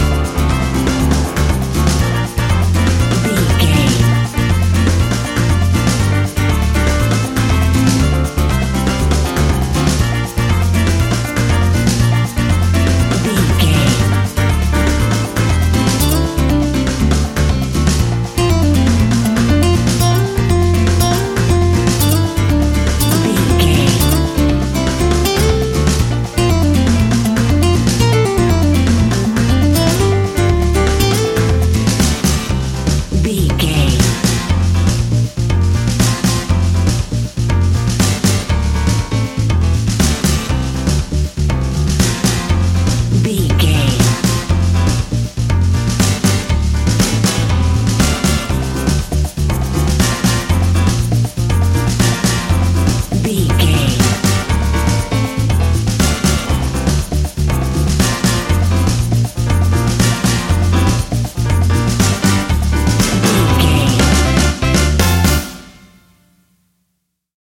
An exotic and colorful piece of Espanic and Latin music.
Aeolian/Minor
flamenco
romantic
maracas
percussion spanish guitar
latin guitar